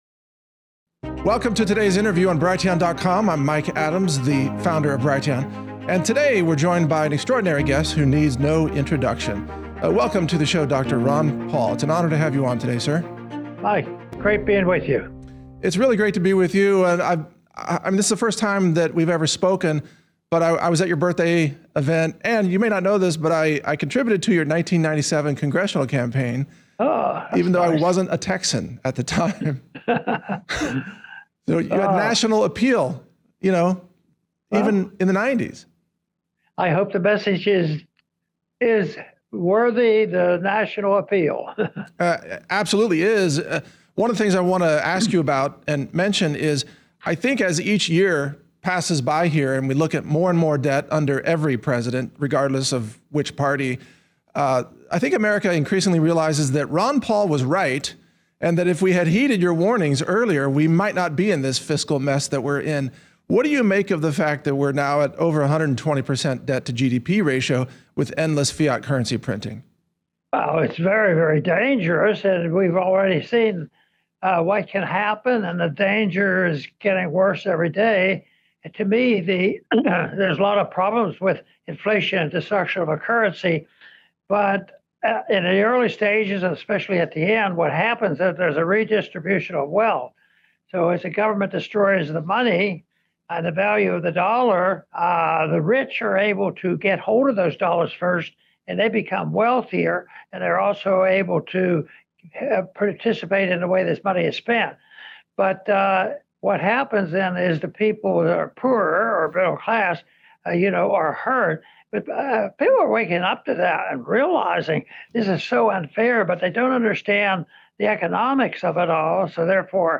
Ron Paul talks with Mike Adams about Trump tariffs, currency printing, flag burning and WARS - Natural News Radio